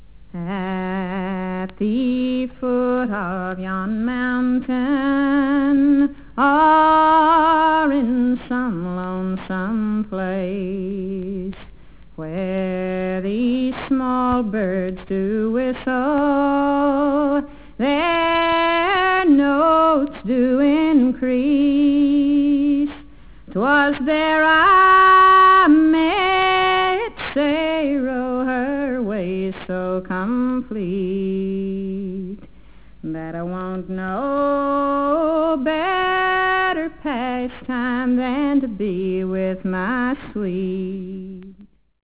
spare vocals